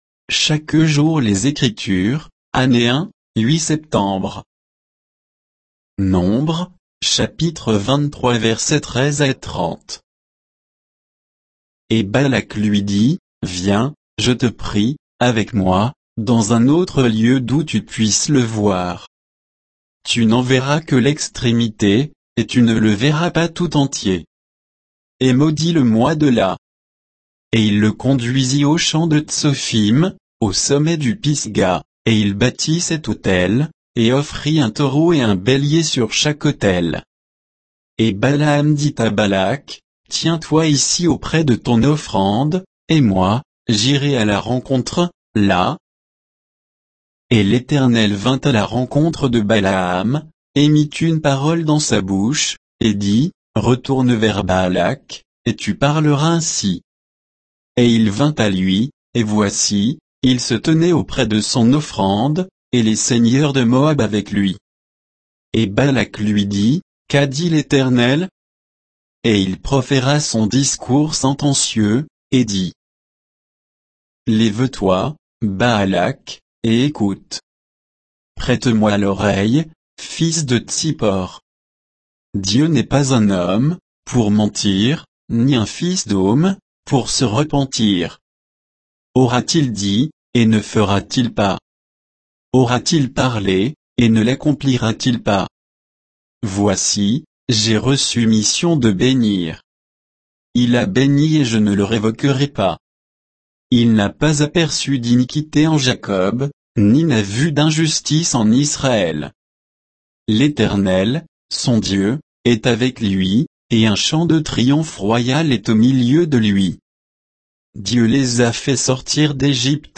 Méditation quoditienne de Chaque jour les Écritures sur Nombres 23